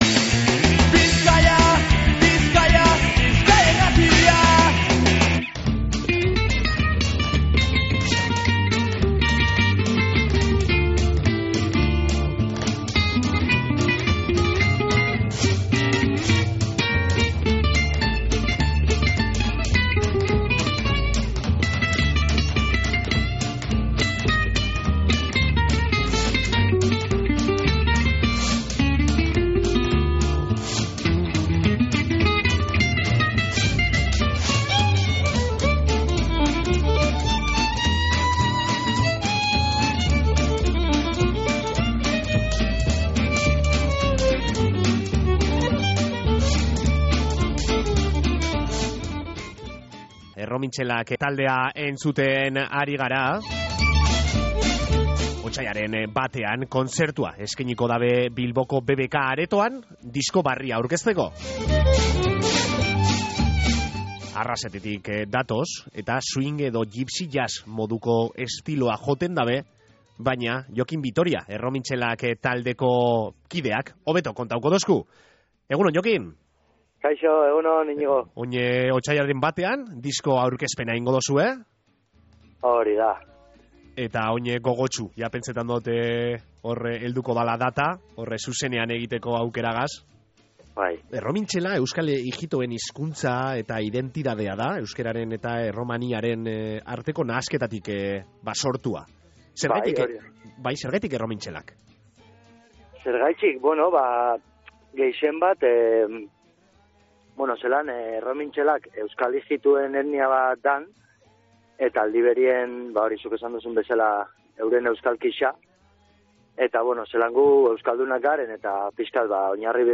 Erromintxelak, swing edo gipsy jazz estiloak euskeraz interpretauta